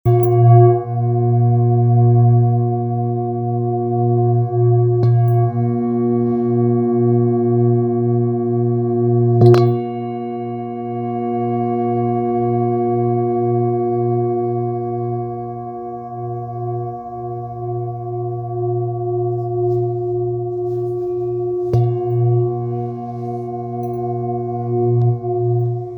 Singing Bowl, Buddhist Hand Beaten, with Fine Etching Carving, Green Tara, Thangka Color Painted, Select Accessories
Material Seven Bronze Metal